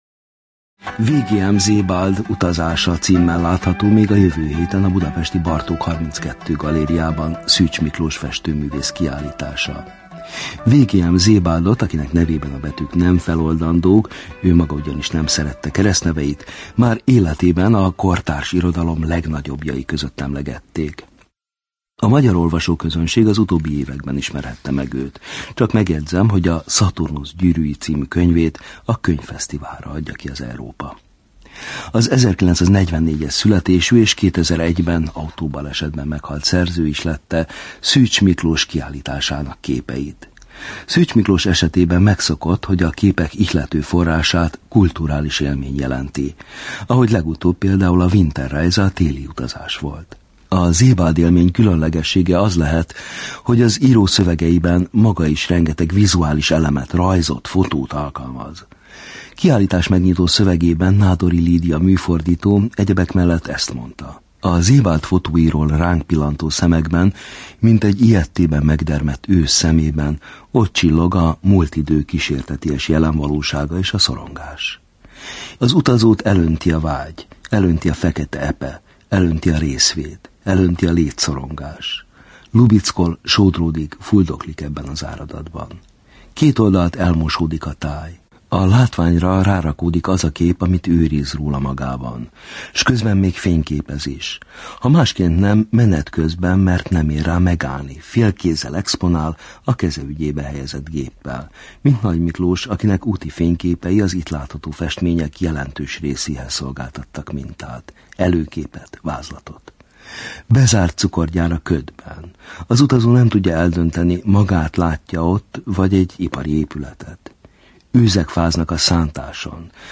beszélgettem ma délelőtt a Bartók Rádióban.